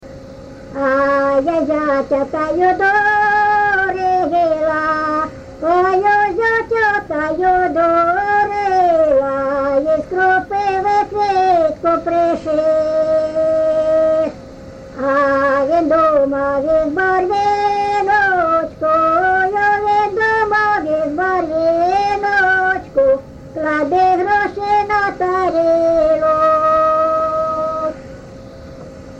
ЖанрВесільні
Місце записус. Ярмолинці, Роменський район, Сумська обл., Україна, Слобожанщина